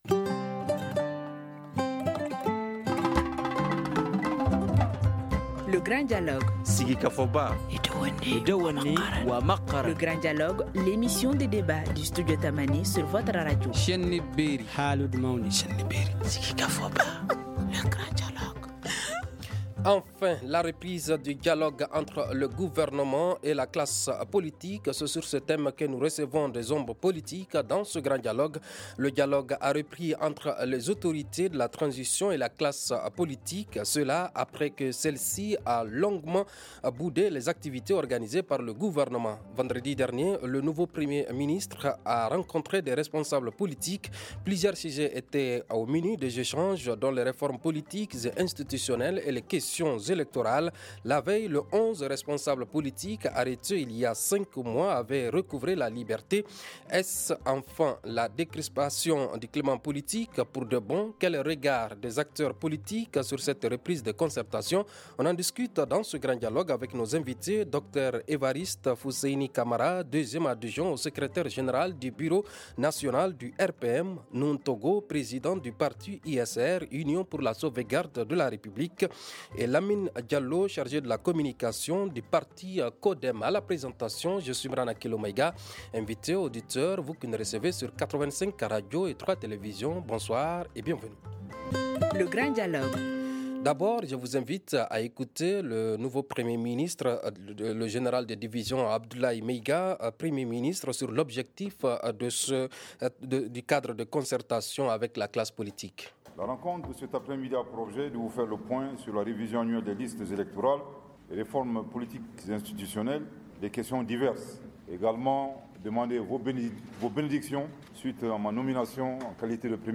On en discute dans ce Grand dialogue avec nos invités :